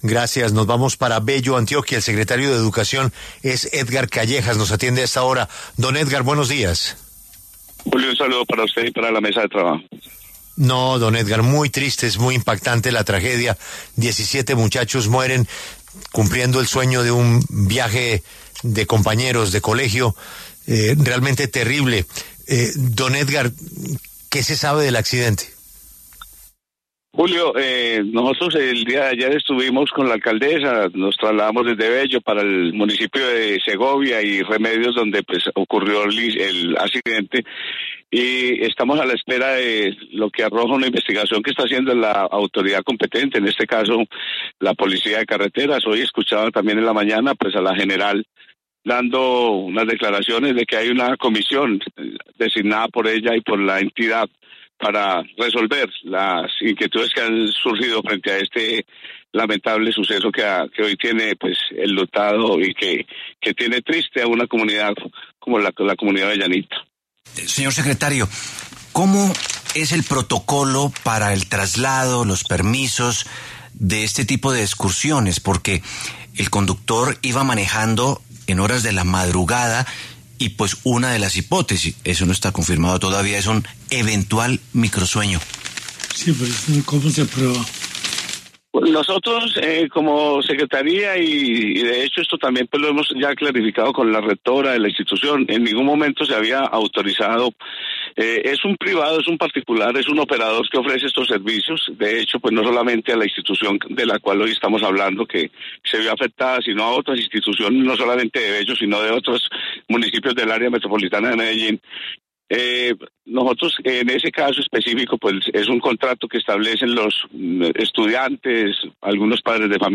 Edgar Callejas Arango, secretario de Educación de Bello, Antioquia, habló en los micrófonos de La W sobre el accidente de un bus que dejó 17 estudiantes muertos.